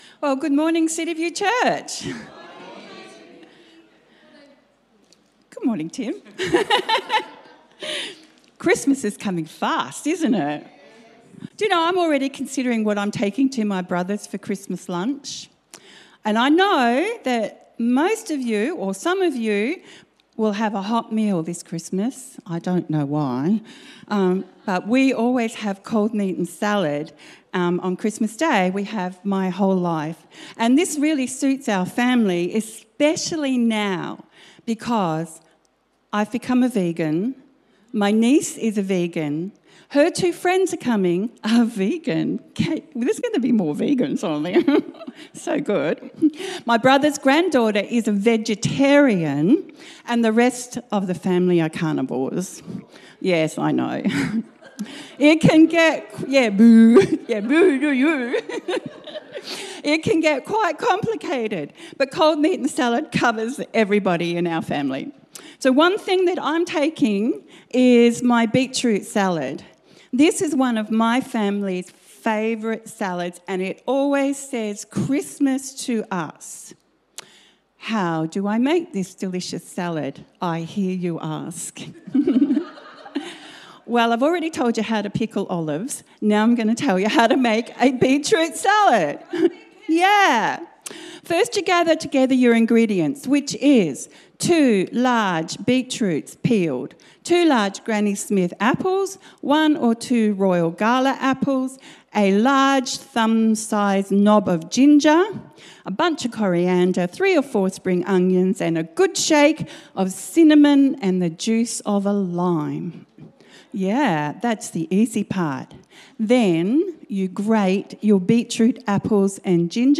Today’s sermon